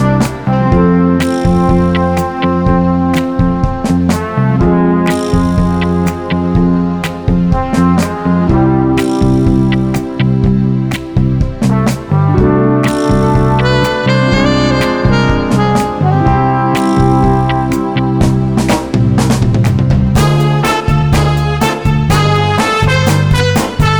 No Backing Vocals Crooners 3:26 Buy £1.50